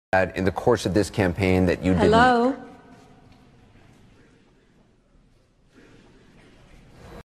melania-trump-hello.mp3